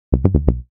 SE（話し声）
話し声。男性。てよてよ。